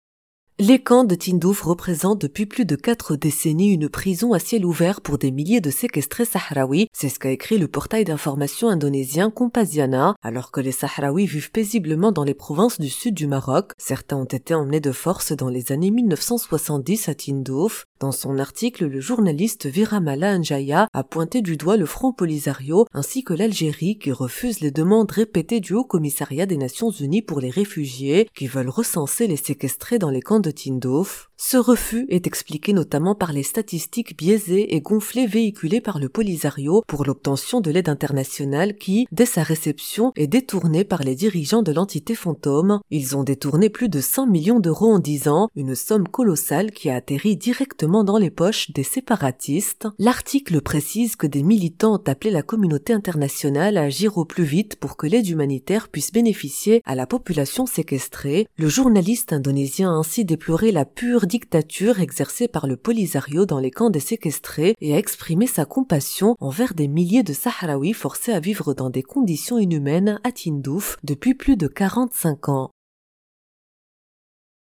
Article à écouter en podcast